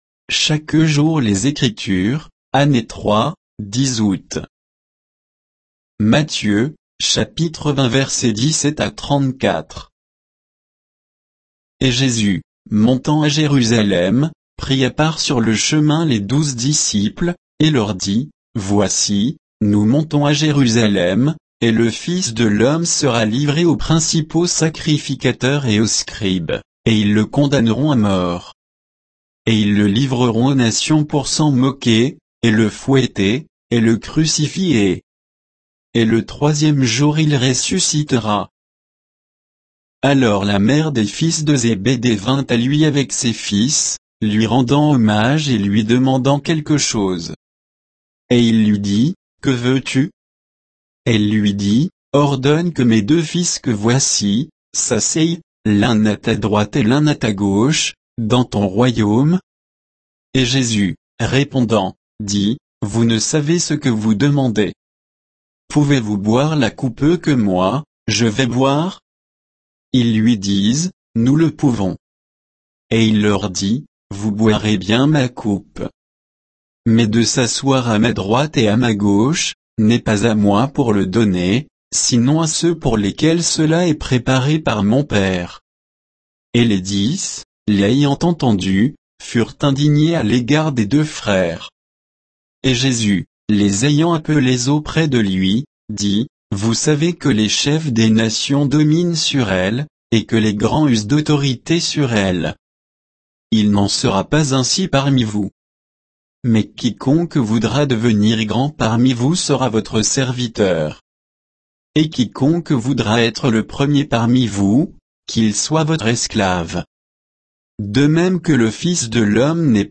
Méditation quoditienne de Chaque jour les Écritures sur Matthieu 20